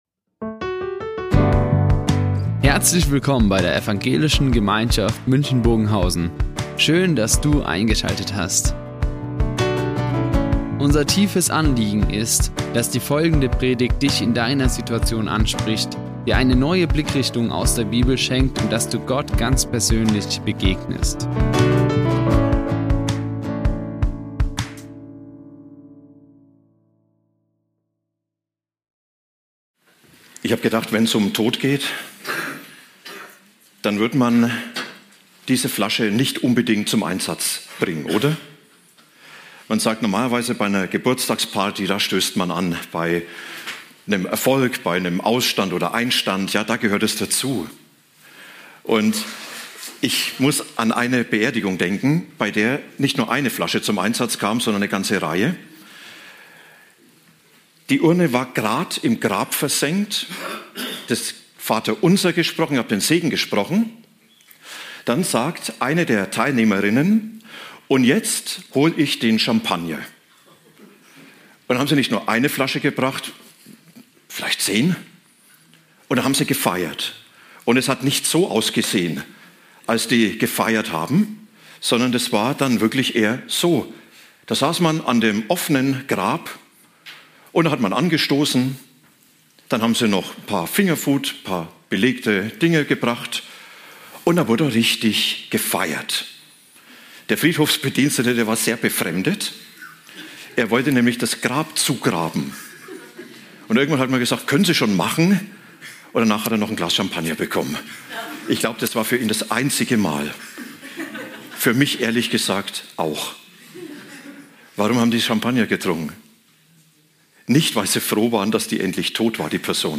EinSPRUCH gegen den Tod - Predigt Johannes 11,25-26; 14,2-3 ~ Ev.